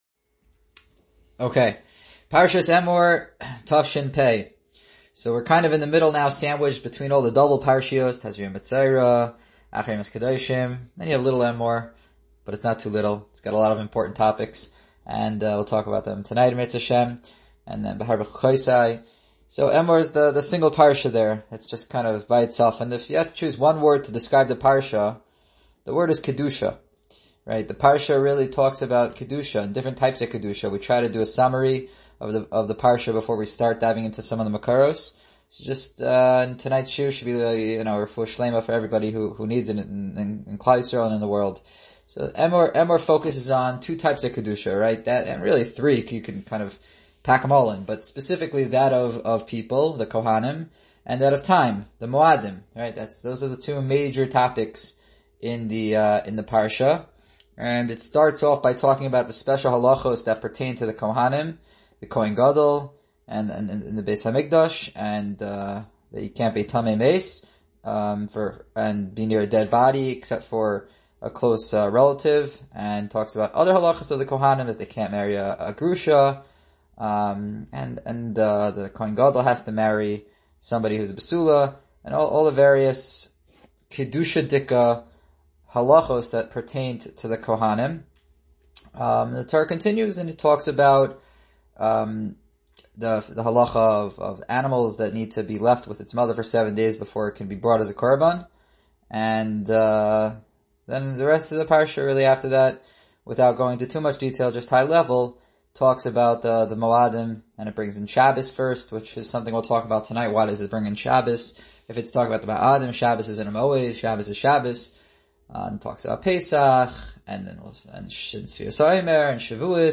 Emor-5780-Shiur-at-BTU.mp3